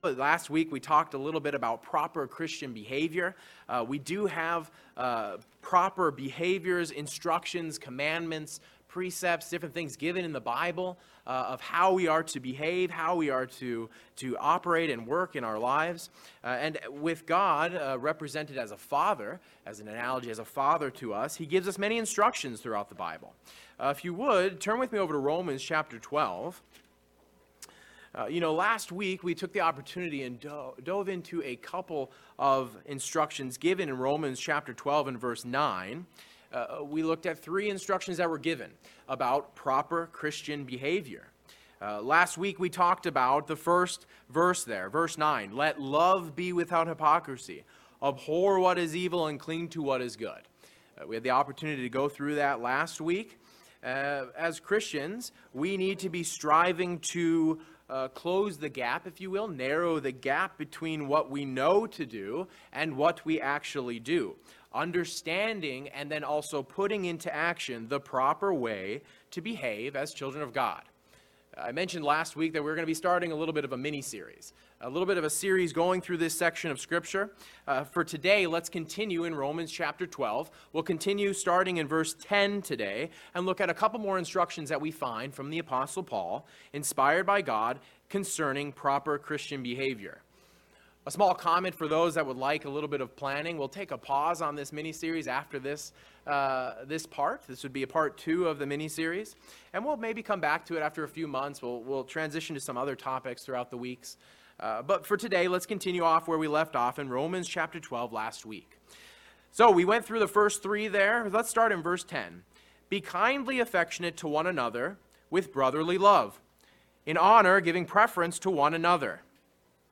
As children of God, we have the profound gift to be instructed by our Heavenly Father through His word. Continuing a mini-series on proper Christian behavior, this sermon looks at three instructions given in Romans 12.